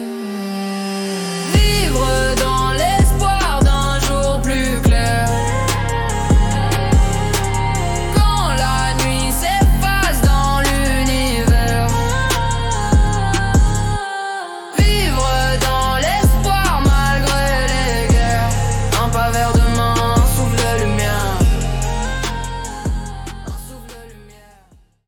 Style : Rap